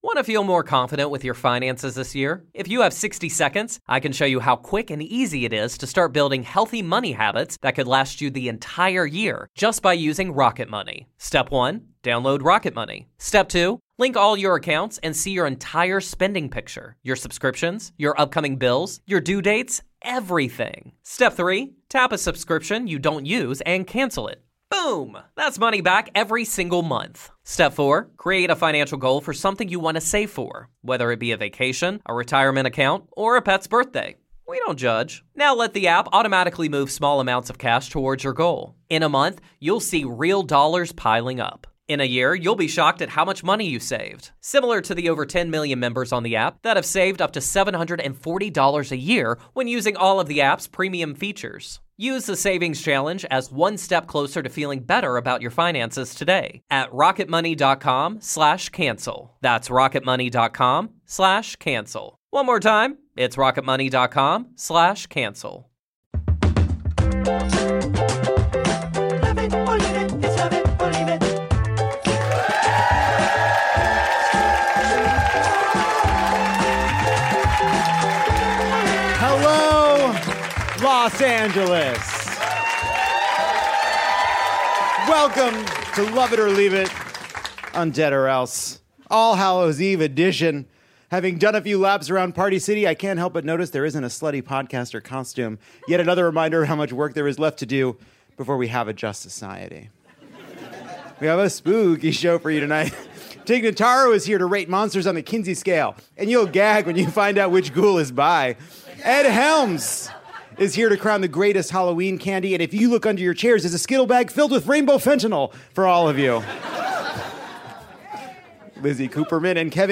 Lovett or Leave It turns L.A.’s Dynasty Typewriter into a Halloween spooktacular during the only week of the year when we don’t mind hearing boos.